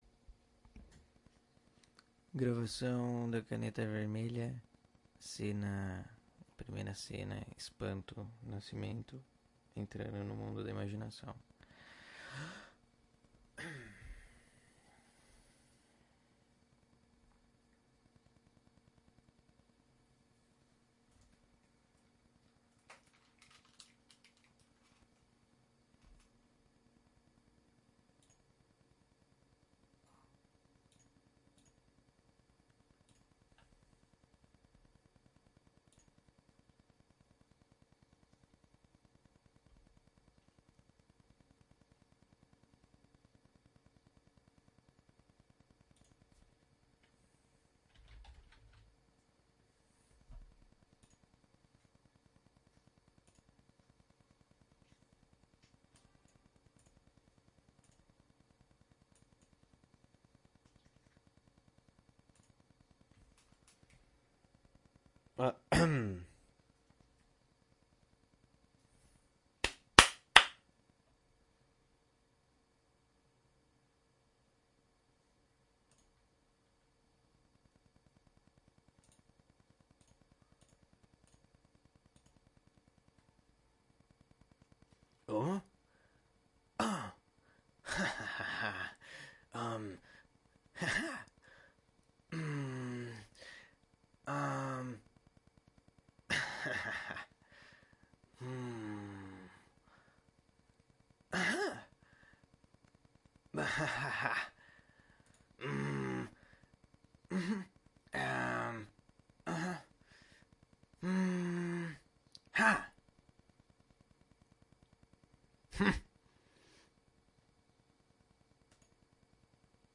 描述：思考男性！请相信。
标签： 声音 声音 人性化 人声 口技 思维
声道立体声